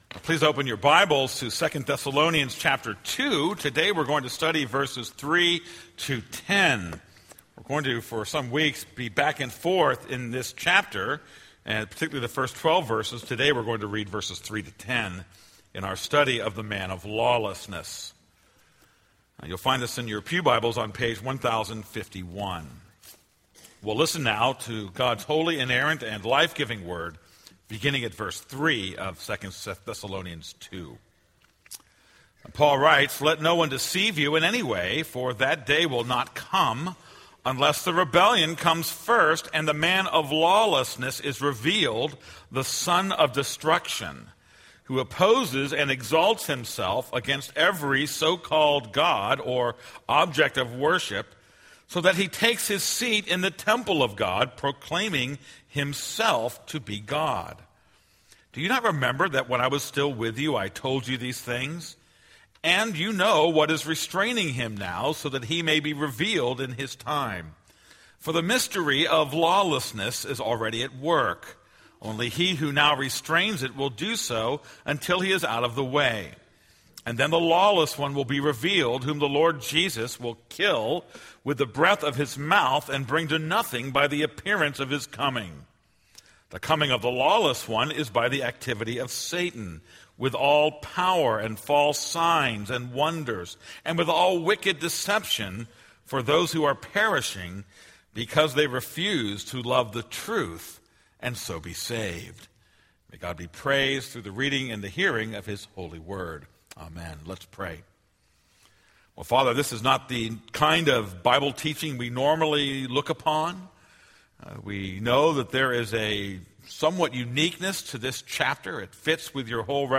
This is a sermon on 2 Thessalonians 2:3-10.